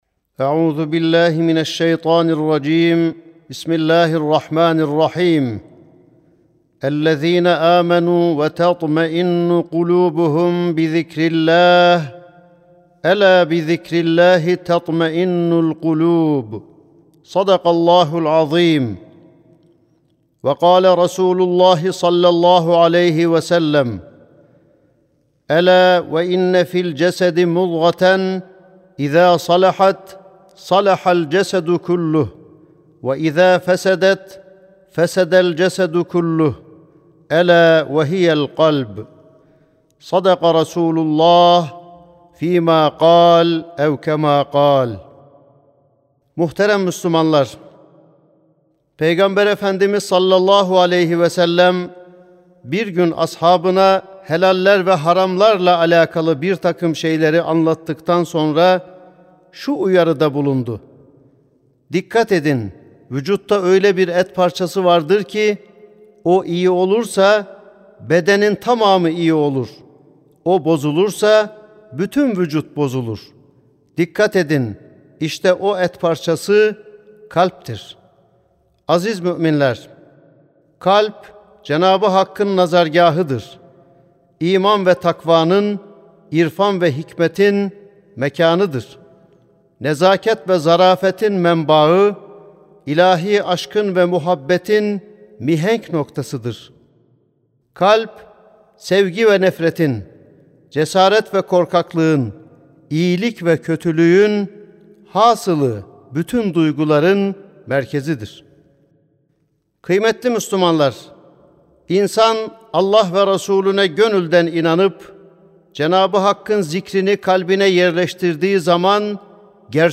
Sesli Hutbe (Cenâb-ı Hakk’ın Nazargâhı, Kalp).mp3